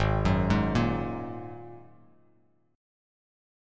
G#7sus2 chord